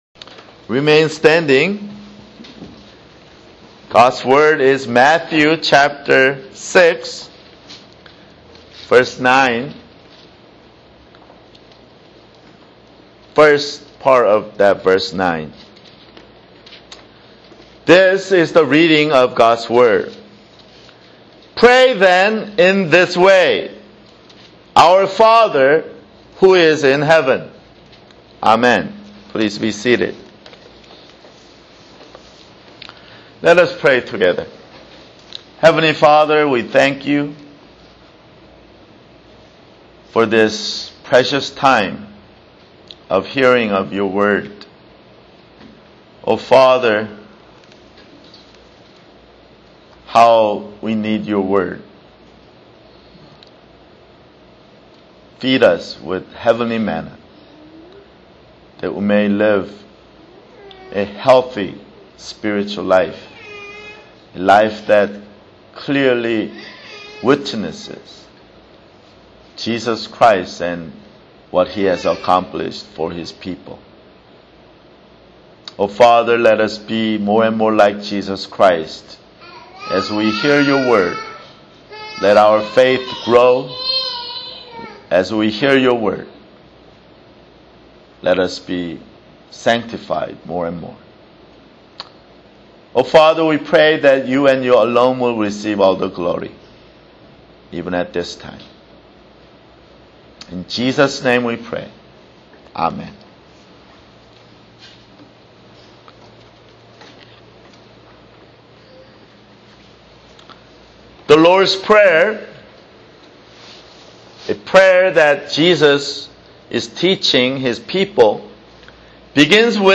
[Sermon] Matthew (34)